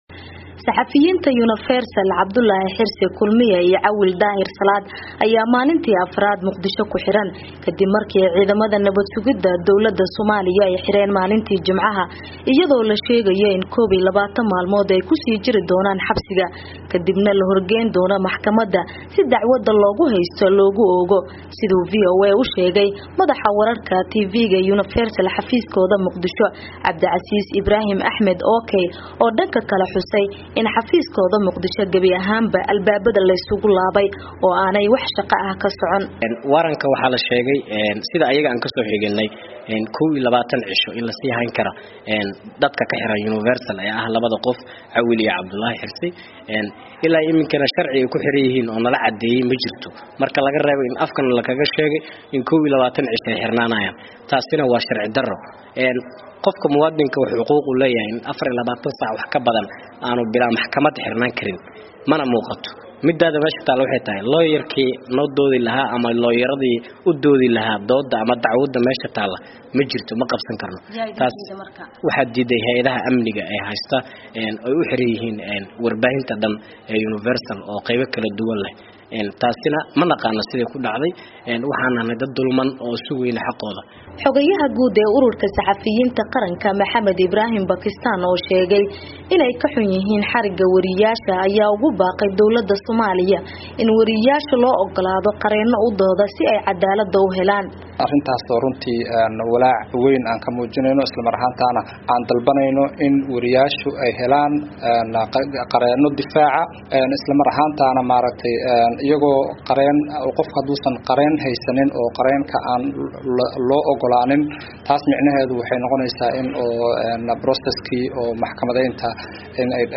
Warbixinta